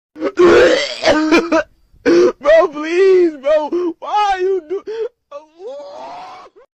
Bro Please Why You Do Crying Sound Effect Free Download